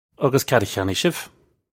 Pronunciation for how to say
Uggus kad uh khyan-nee shiv? (U)
This is an approximate phonetic pronunciation of the phrase.